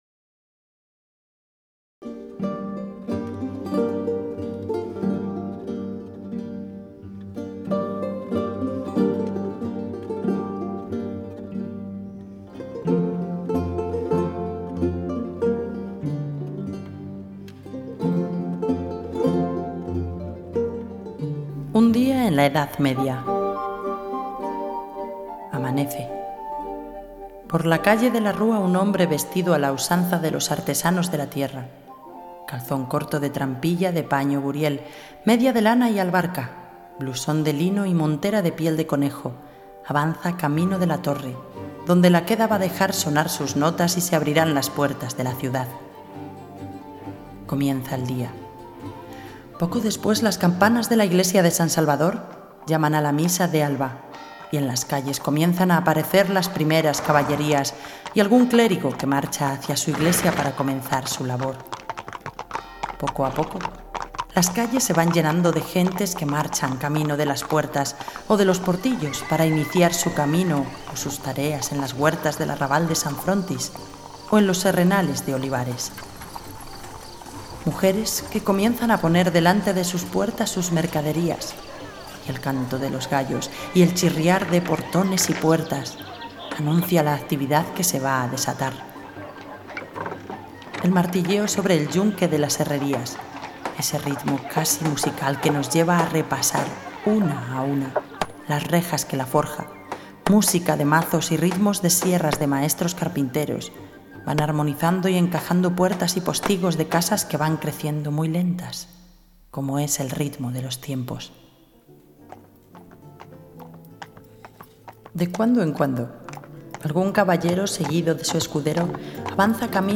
Narración: Un día en la Edad Media